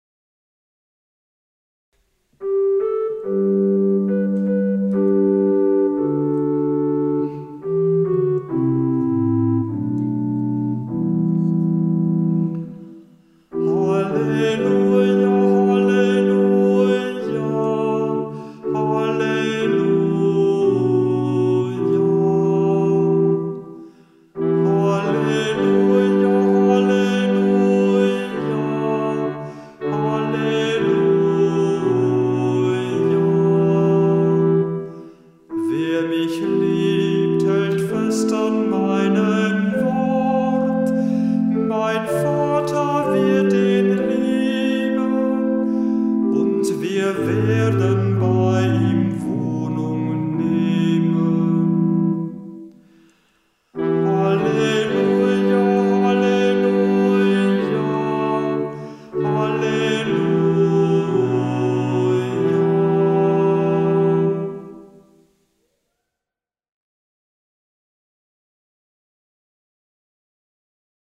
Ruf vor dem Evangelium - November 2024
Kantor der Verse